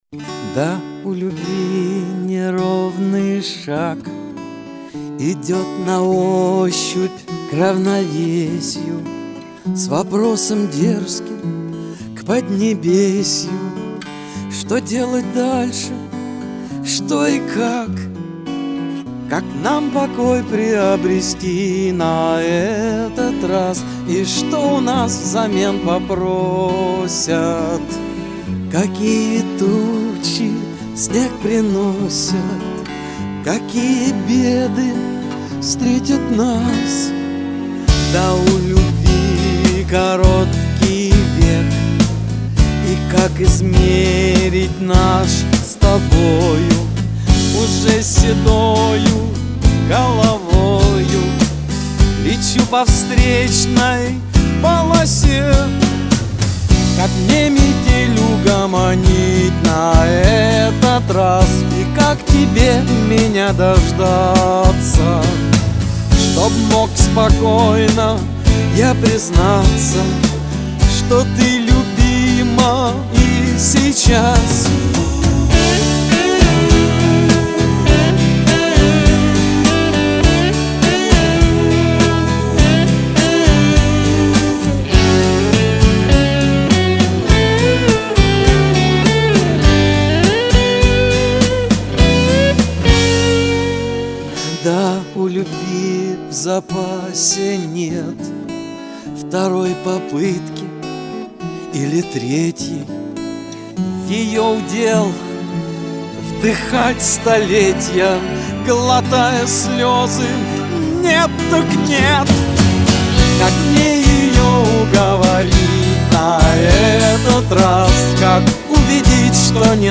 Ударник взят из библиотеки.
Слишком много муси-пуси.
На самом деле, красиво.
А мне понравилось, как ты спел: деликатно, не брутально.